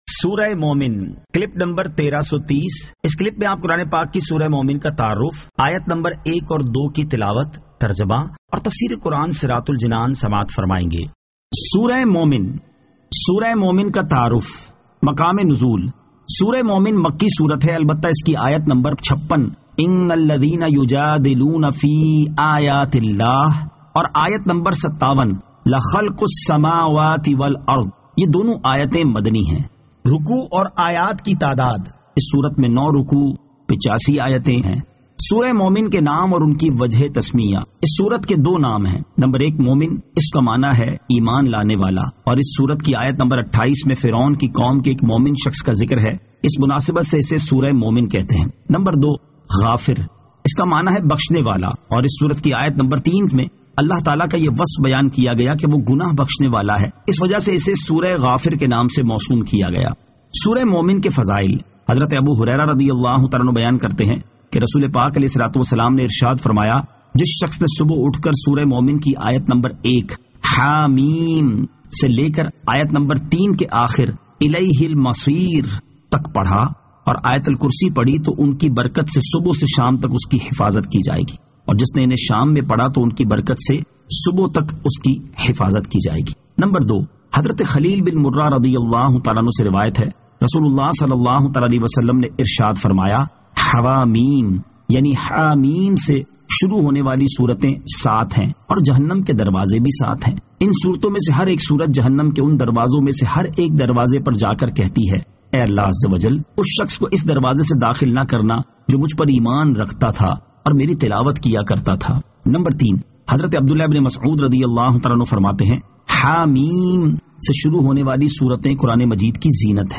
Surah Al-Mu'min 01 To 02 Tilawat , Tarjama , Tafseer